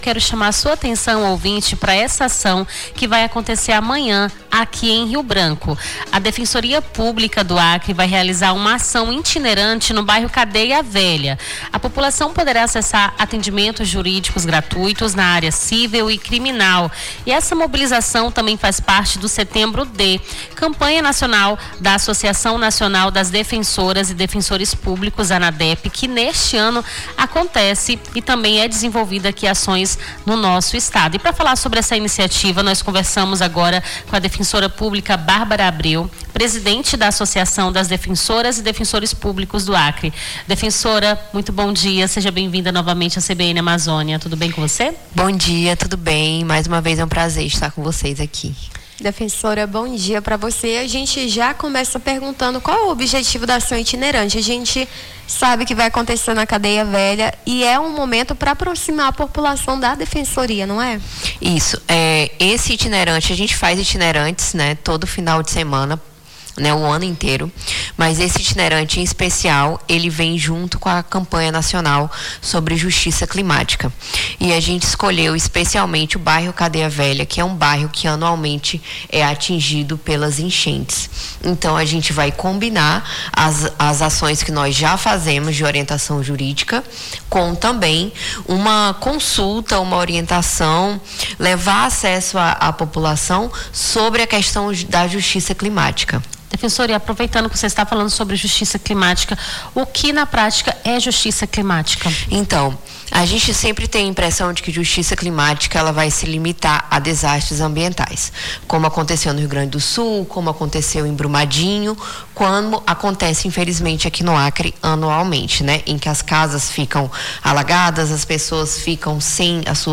Nome do Artista - CENSURA - ENTREVISTA AÇÃO DEFENSORIA CADEIA VELHA (12-09-25).mp3